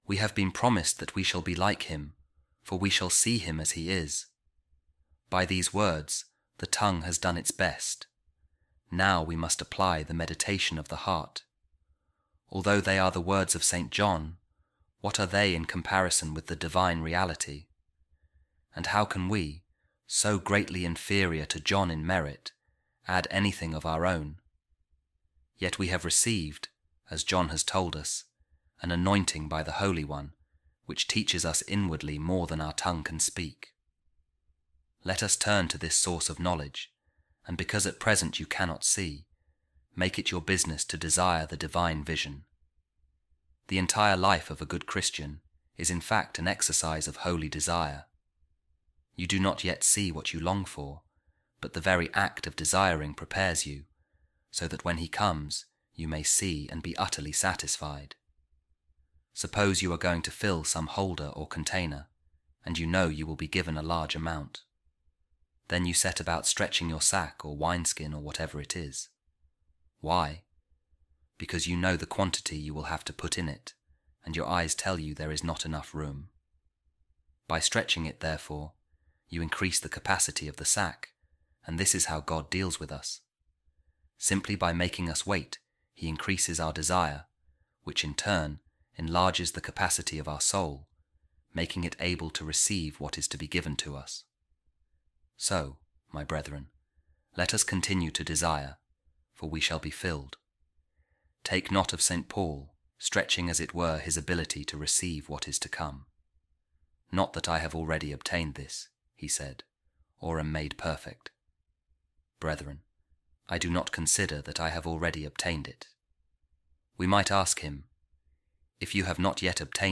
A Reading From The Treatise Of Saint Augustine On The First Letter Of Saint John | How The Heart Yearns For God